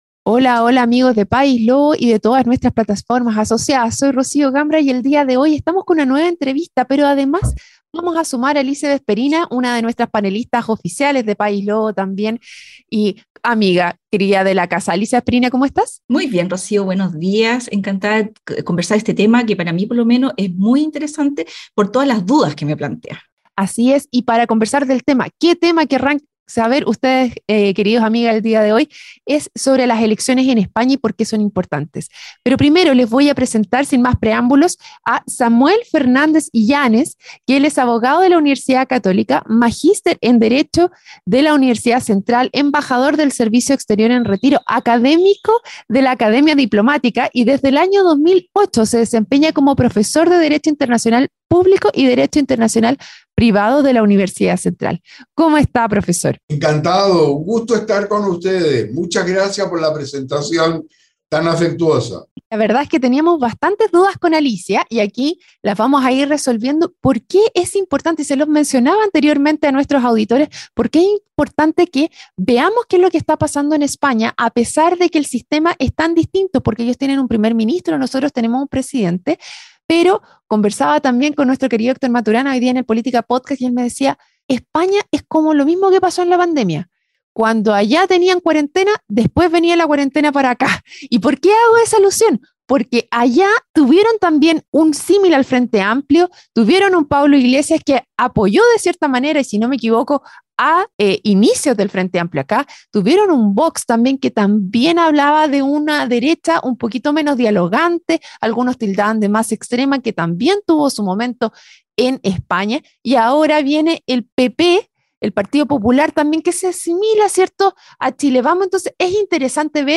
La conversación